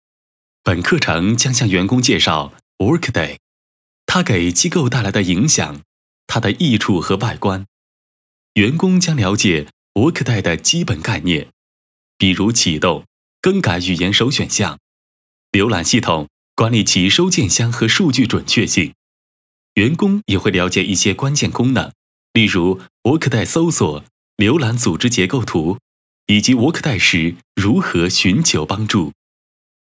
Warm, bright, compellent, smooth, professional.
Sprechprobe: eLearning (Muttersprache):